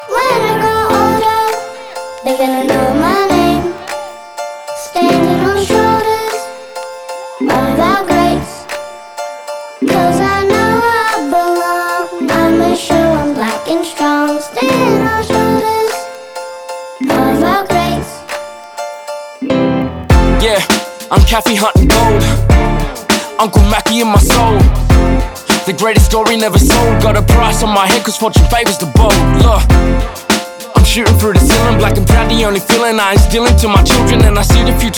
пробивного бита и лаконичного сэмпла
Hip-Hop Rap
Жанр: Хип-Хоп / Рэп